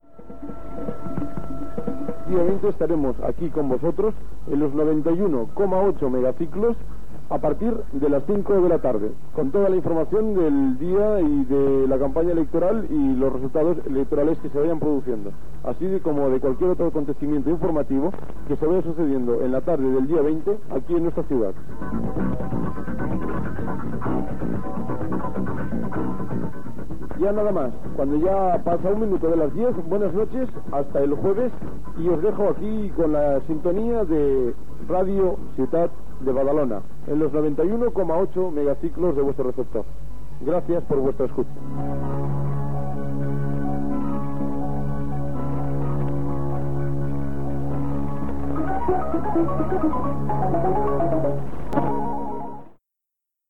Avís especial eleccions al Parlament de Catalunya i fi d'emissió.
FM